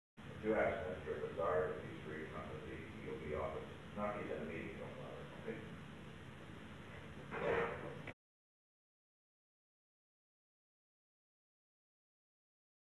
Secret White House Tapes
Location: Executive Office Building
The President talked with the White House operator.